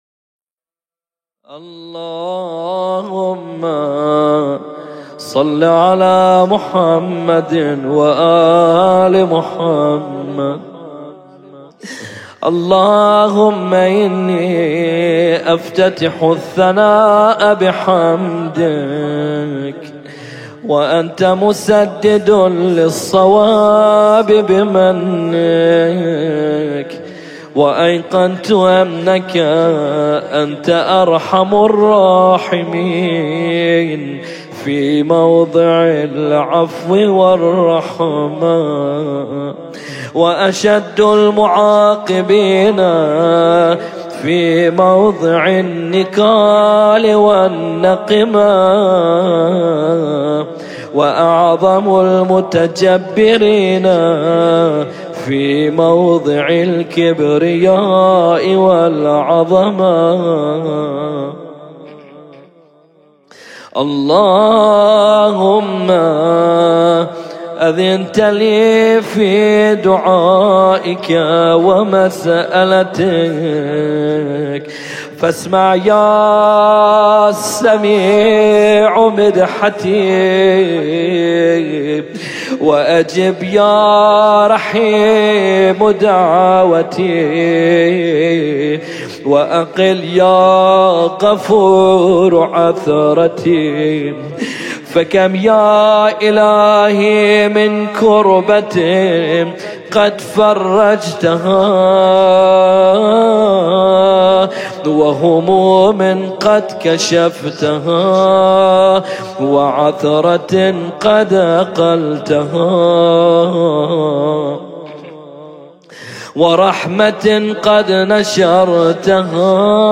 قرائت دعای افتتاح